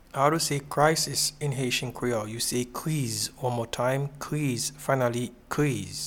Pronunciation and Transcript:
Crisis-in-Haitian-Creole-Kriz.mp3